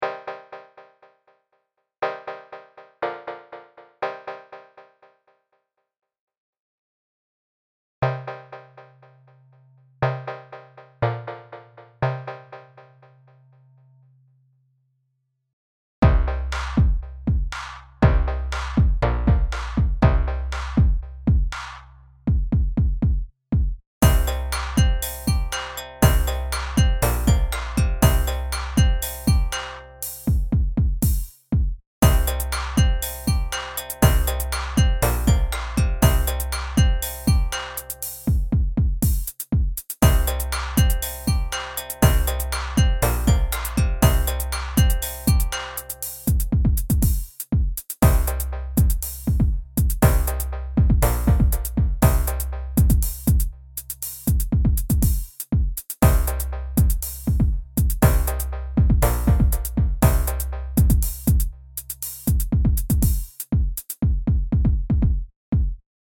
Bucle Break Beat
melodía
repetitivo
sintetizador
Sonidos: Música